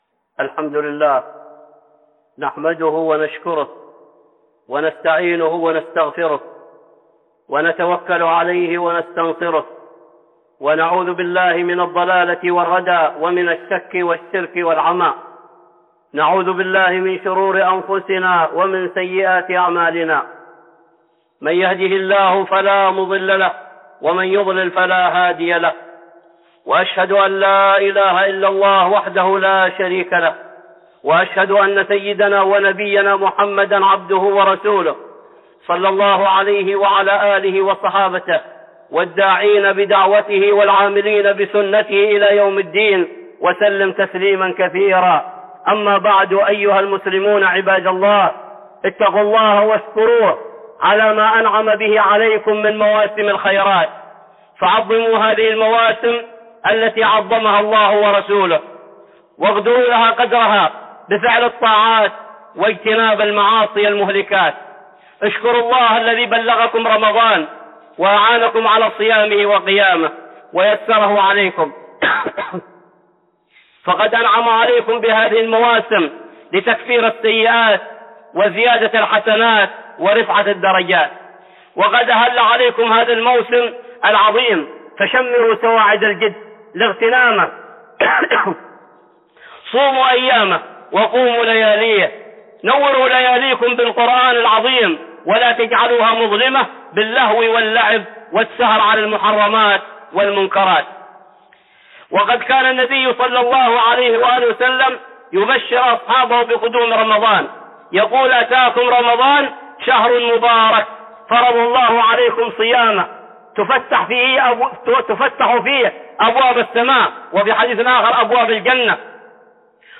(خطبة جمعة) فضائل رمضان